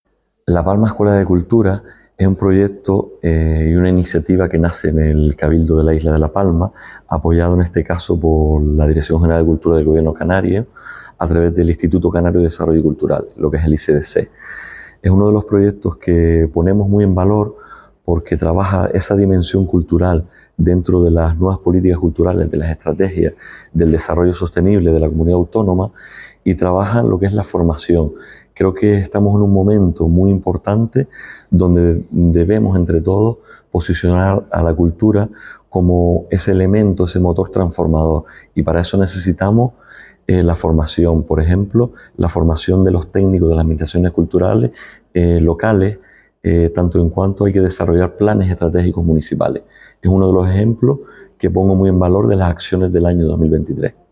Declaraciones_audio_Rubén_Pérez_La_Palma_Escuela_de_Cultura.mp3